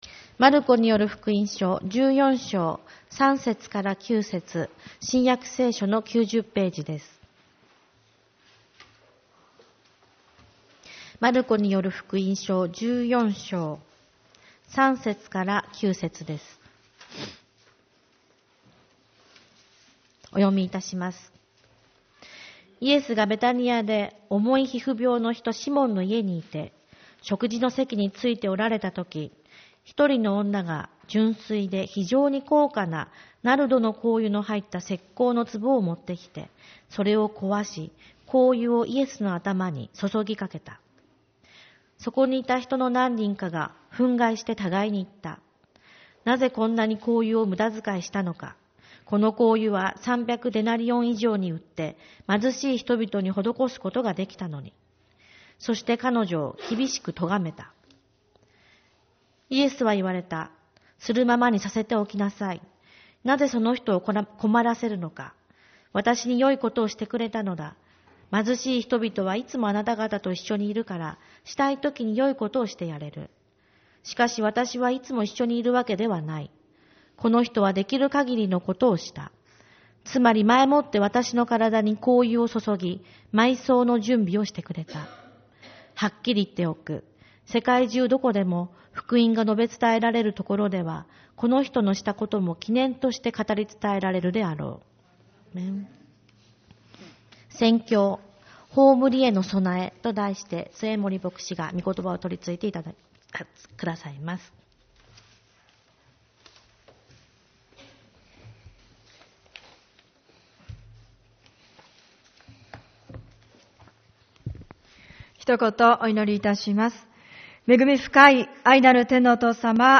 主日礼拝 「葬りへの道」 マルコによる福音書14:3-9